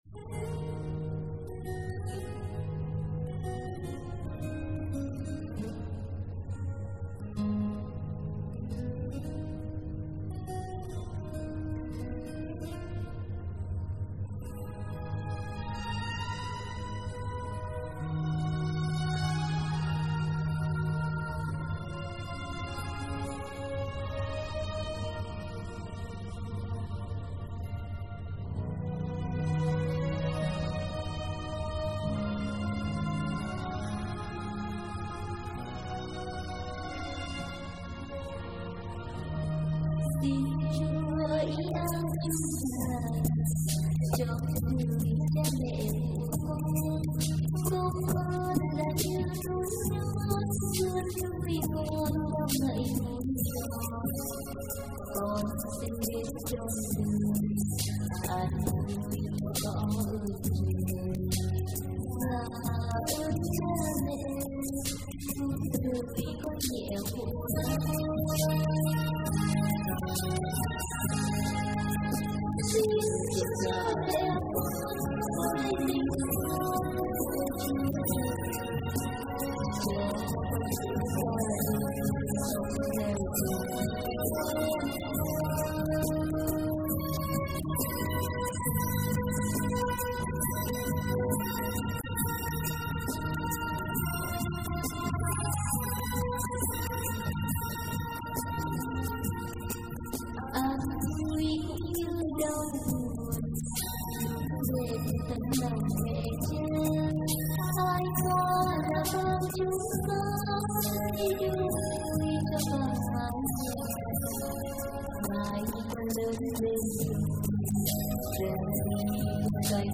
thể hiện hơn 90 bài hát Thánh Ca.